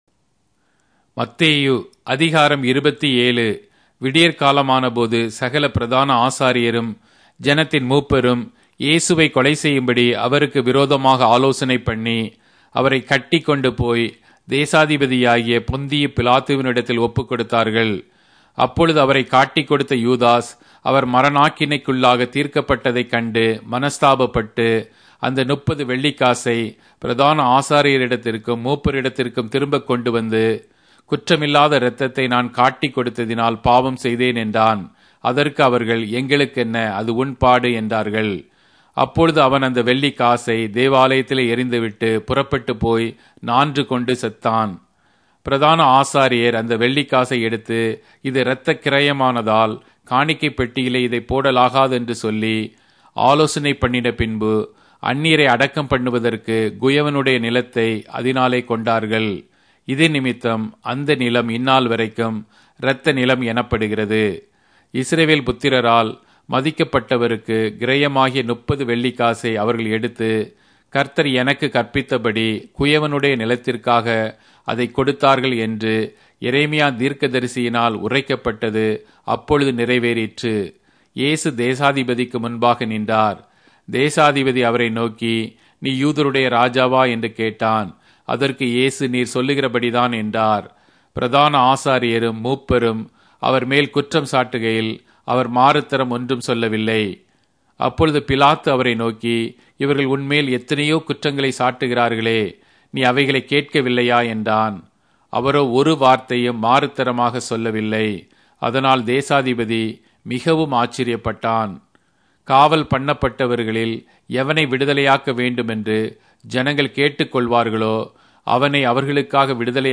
Tamil Audio Bible - Matthew 10 in Ervhi bible version